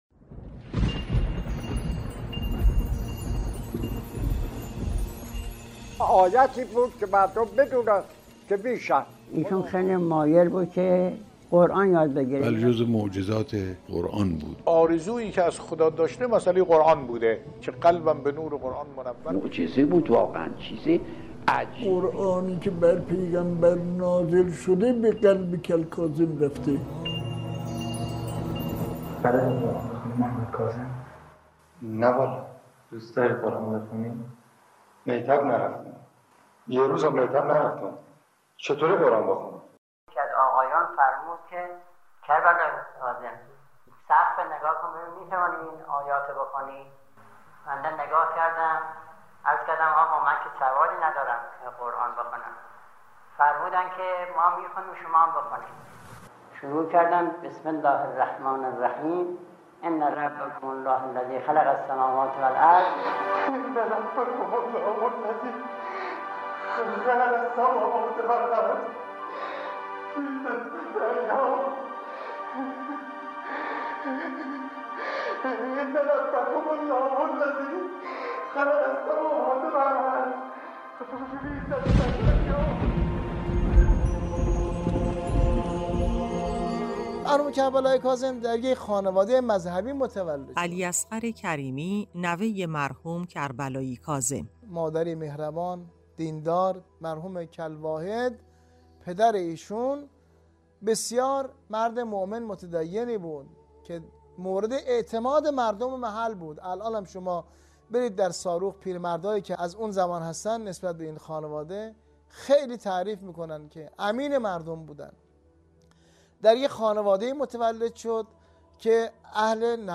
ایکنا ـ درباره سابقه فعالیت خود با شبکه رادیویی قرآن بگویید.